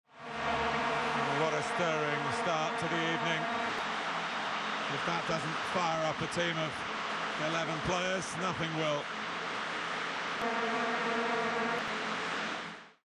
The beeswarm drone of thousands of soccer fans blowing away at these horns drowns out everything else.
With a few days left to the World Cup Finals, Prosoniq has introduced VuvuX, a filter that specifically removes Vuvuzela pollution from audio: The process used in VuvuX has been developed to distinguish between voice, Vuvuzela hum and background noise by applying pattern detection and tracking.
vuvuxinaction.mp3